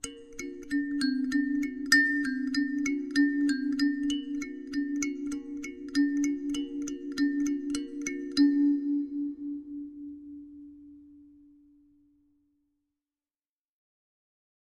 Kalimba, Exotic Melody - Long